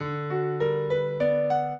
minuet4-3.wav